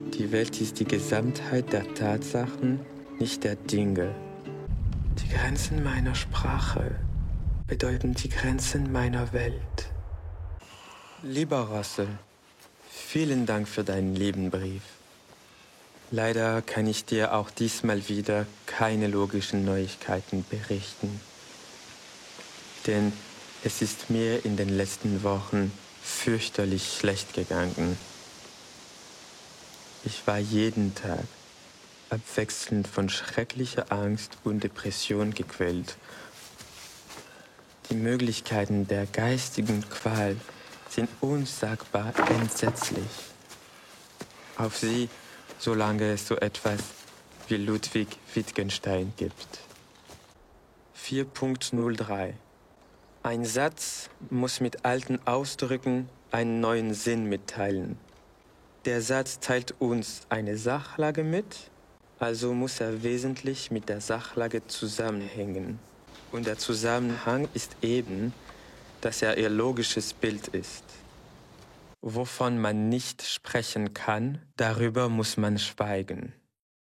Bandes-son
Bande-son ALLEMAND
Voix off
25 - 35 ans - Baryton-basse